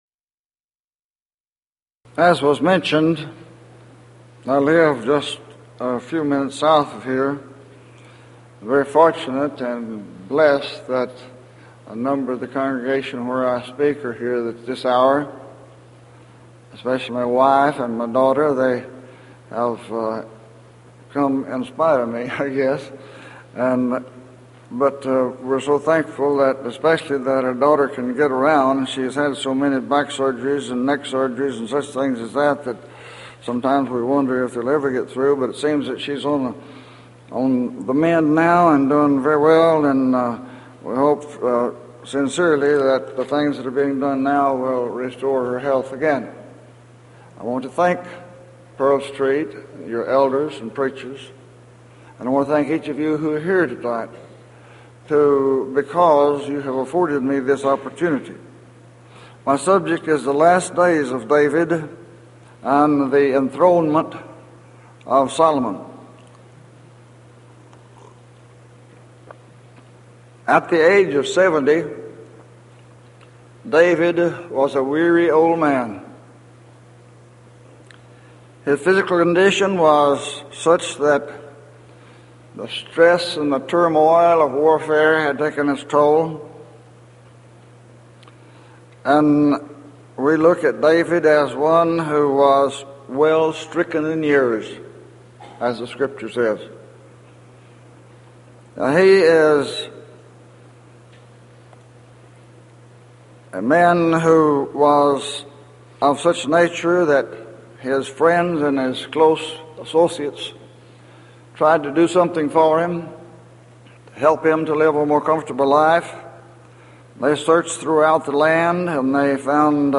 Event: 1993 Denton Lectures Theme/Title: Studies In I & II Kings, I & II Chronicles